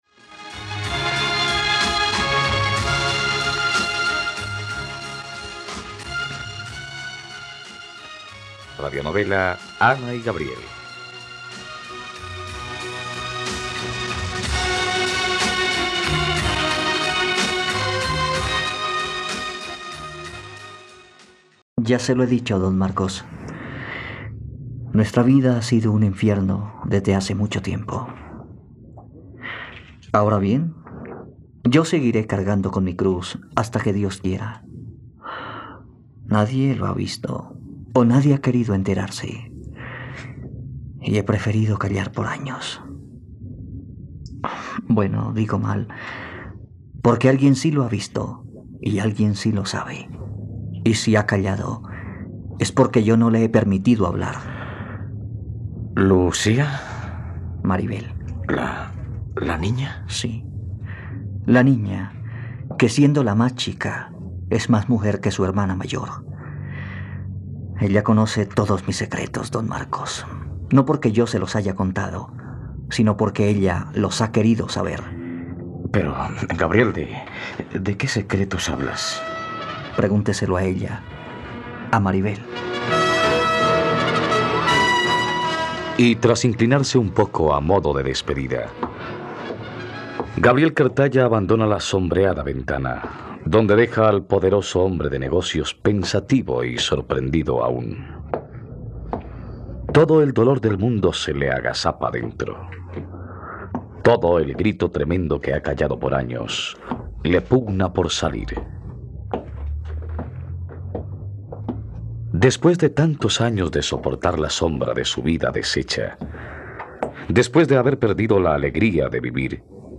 ..Radionovela. Escucha ahora el capítulo 65 de la historia de amor de Ana y Gabriel en la plataforma de streaming de los colombianos: RTVCPlay.